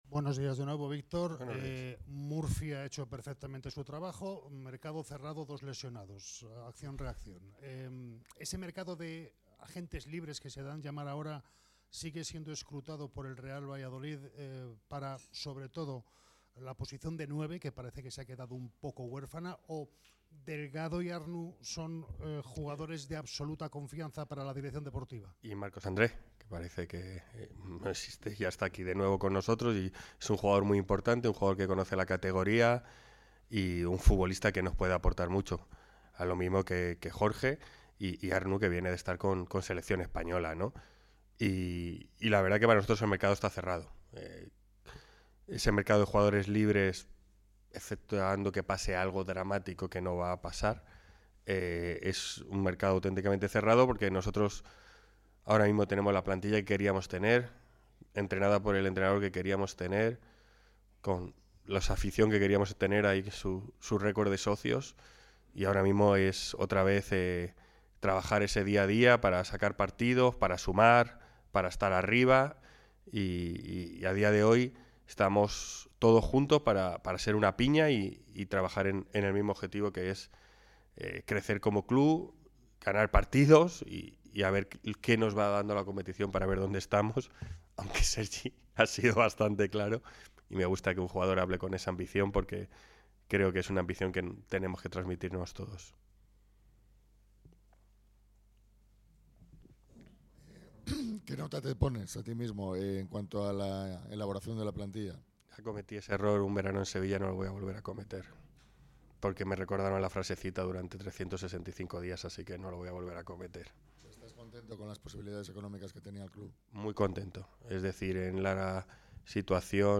Los tres últimos refuerzos del Real Valladolid fueron presentados en la mañana de este miércoles en la sala de prensa del Estadio José Zorrilla.